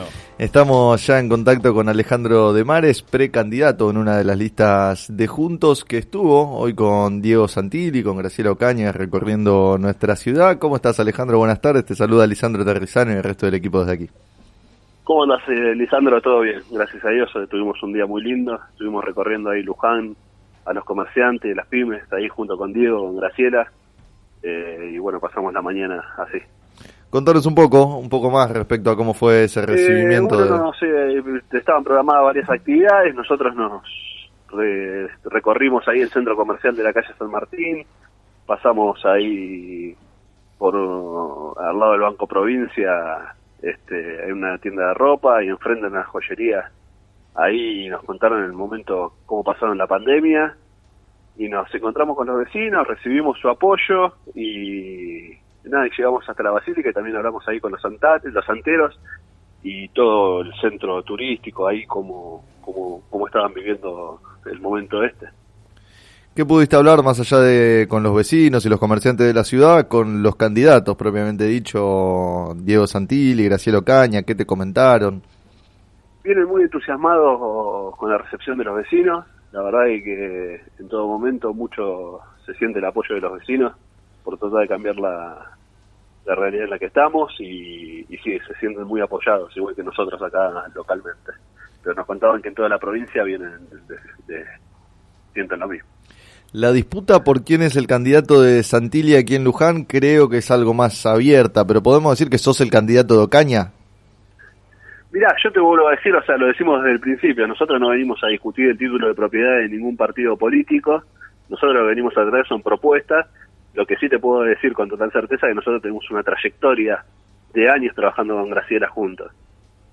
Entrevistado en el programa “Sobre las cartas la mesa” de FM Líder 97.7,